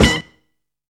BLUES STAB.wav